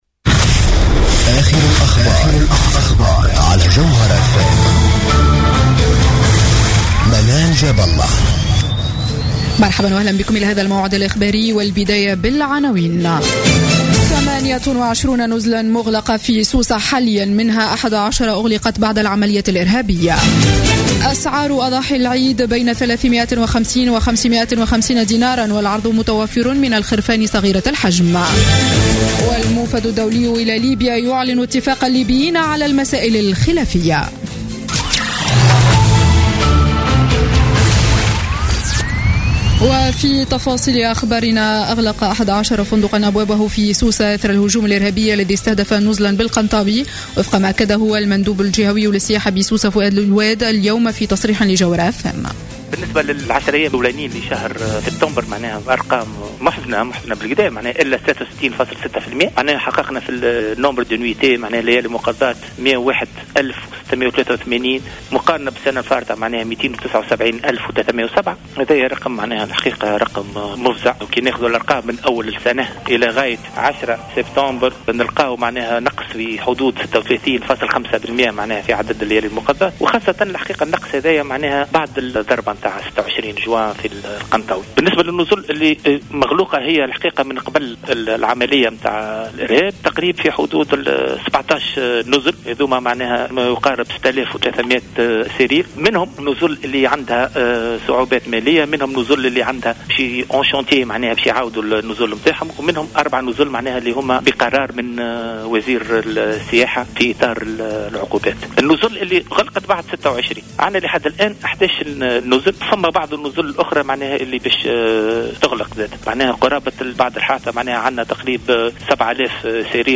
نشرة أخبار السابعة مساء ليوم الجمعة 18 سبتمبر 2015